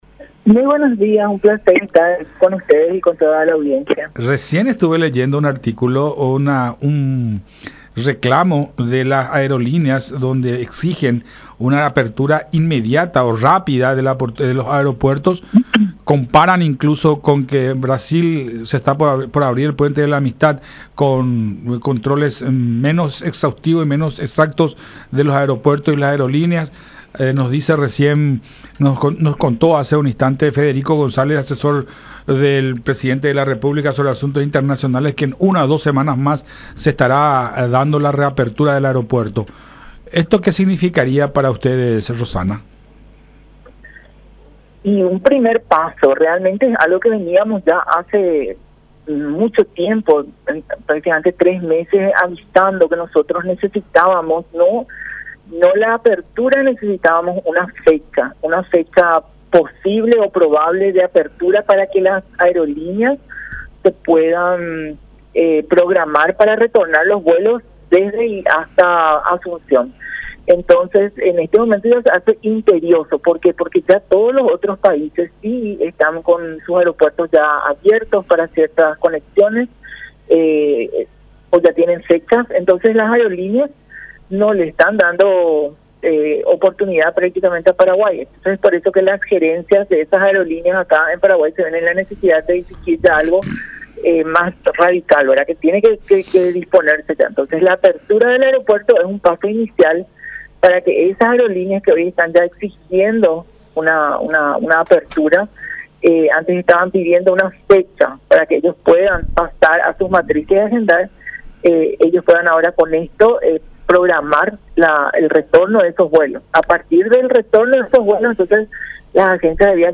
en conversación con La Unión